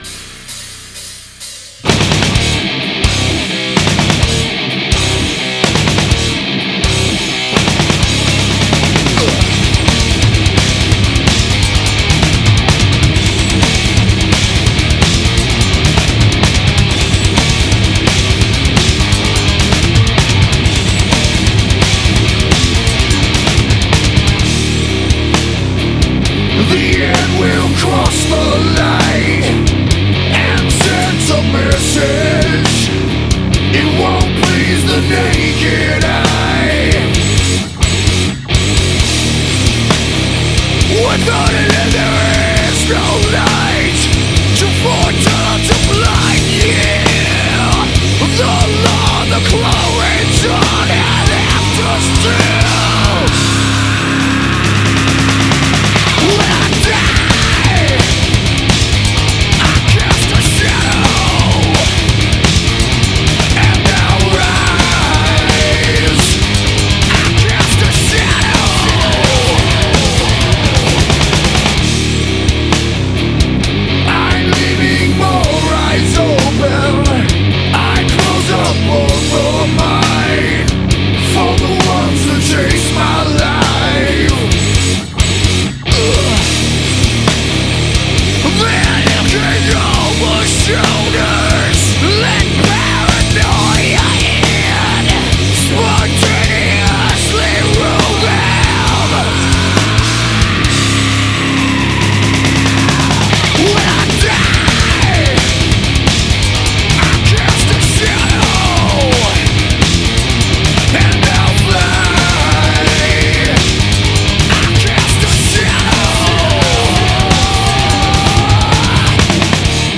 گروو متال
Groove metal